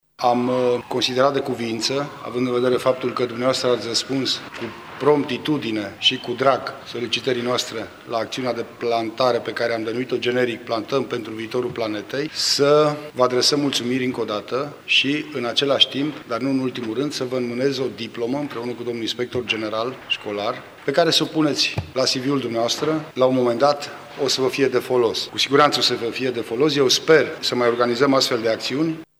În cadrul ceromoniei de premiere, prefectul judeţului Mureş, Lucian Goga, a ţinut să le mulţumească elevilor şi studenţilor participanţi pentru faptul că se implică în astfel de proiecte: